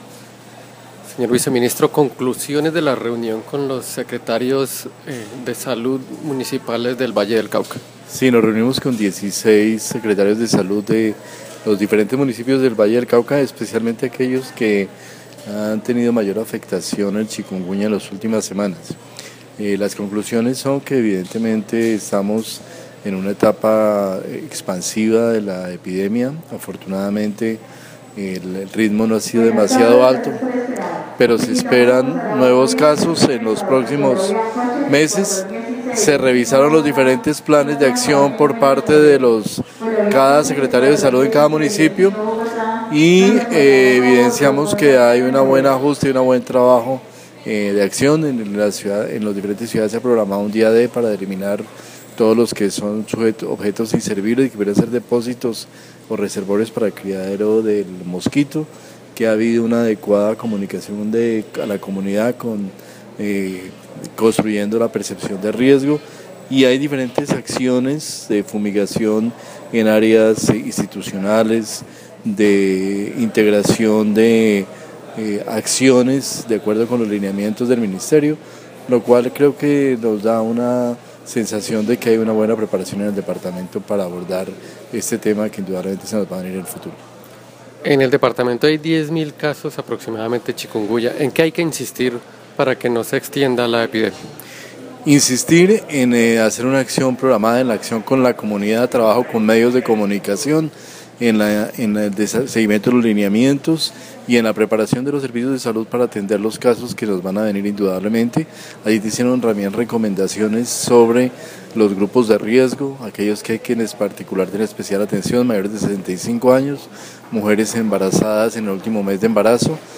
Viceministro de Salud y Prestación de Servicios, Fernando Ruíz Gómez.
Audio: conclusiones de ViceSalud tras reunión de chikunguña en Valle del Cauca